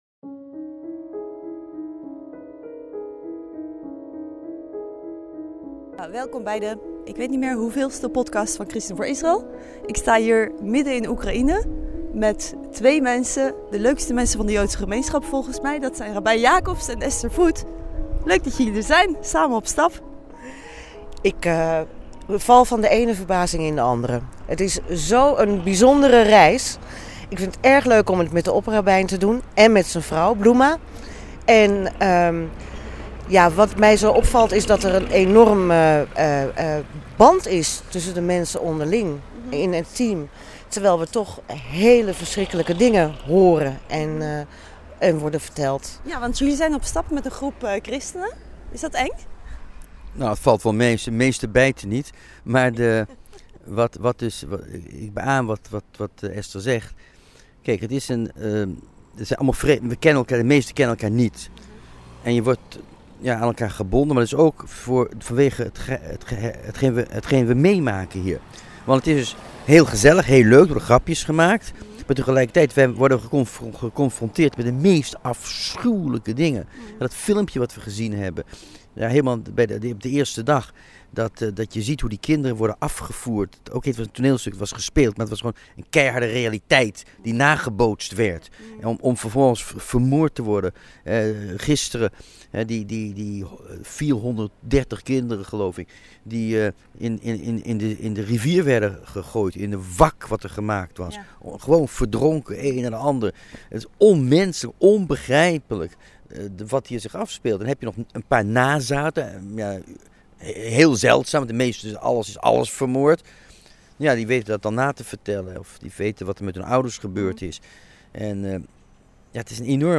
Beluister hier hun bijzondere ervaringen, verteld met veel humor en ook ernst.